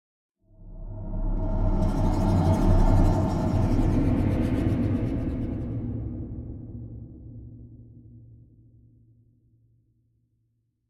Minecraft Version Minecraft Version snapshot Latest Release | Latest Snapshot snapshot / assets / minecraft / sounds / ambient / nether / basalt_deltas / twist4.ogg Compare With Compare With Latest Release | Latest Snapshot